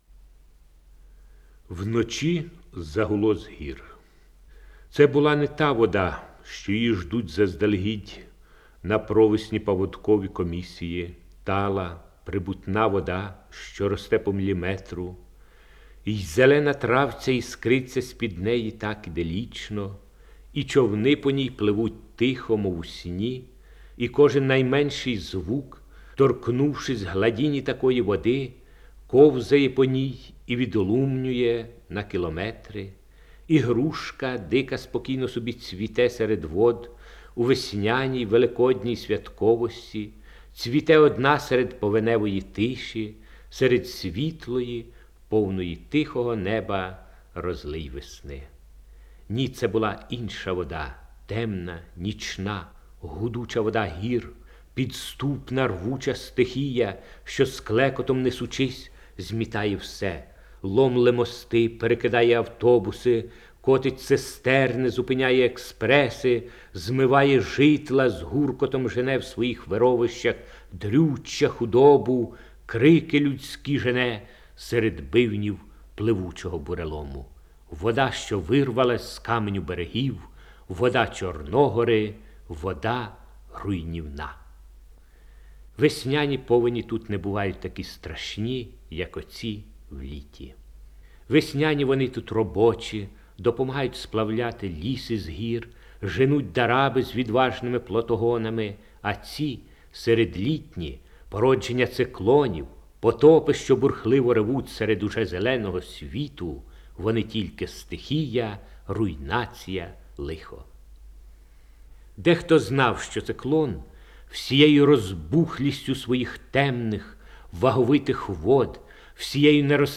Index of /storage/Oles_Gonchar/Записи голосу Гончара